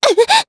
Lorraine-Vox_Damage_jp_02.wav